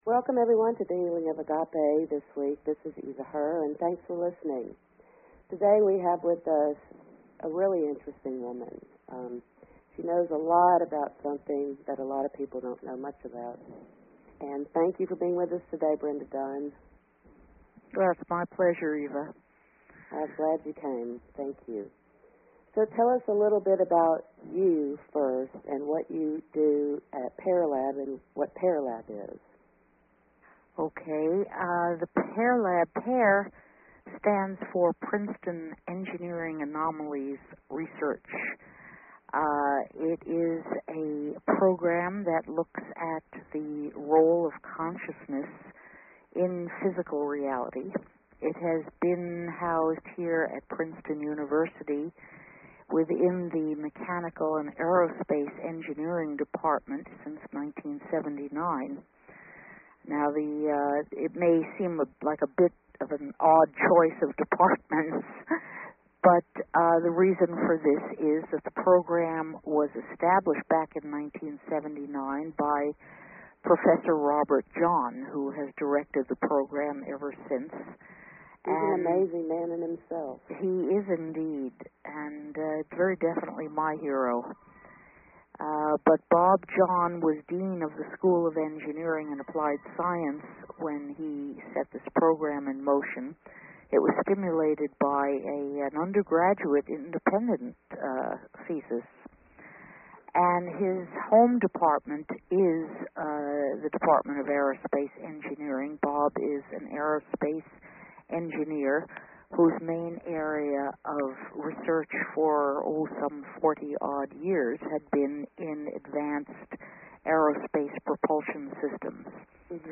Talk Show Episode, Audio Podcast, The_Infinite_Consciousness and Courtesy of BBS Radio on , show guests , about , categorized as